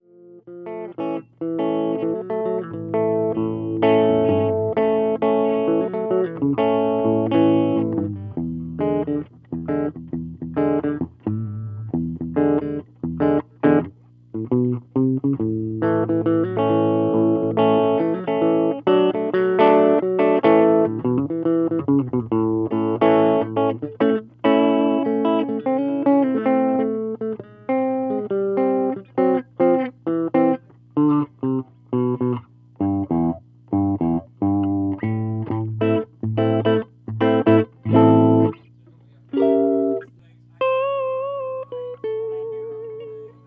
These were taken straight from mic-to-recording, with no processing.
Mellow Humbucker